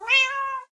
meow3.ogg